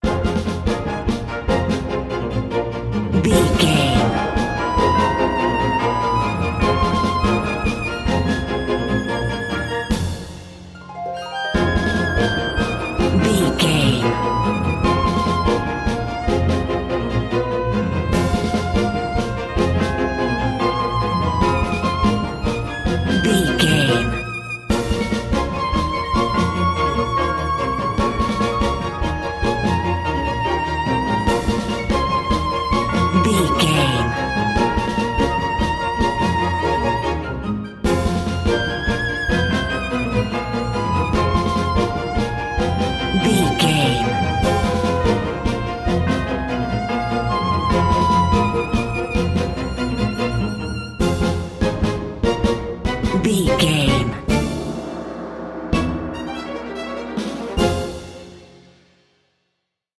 Ionian/Major
Fast
orchestra
violin
brass section
cello
piccolo
drums
harp